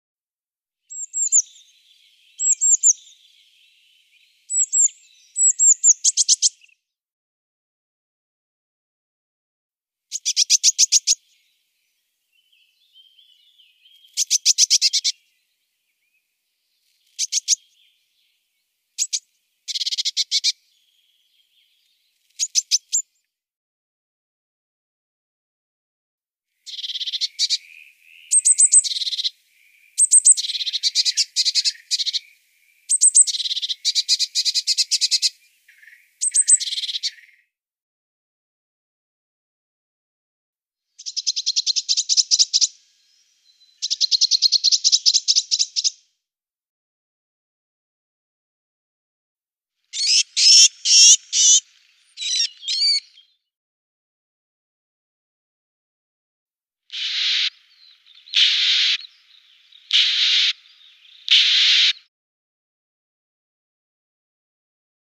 Meisenrufe：-Blaumeise.mp3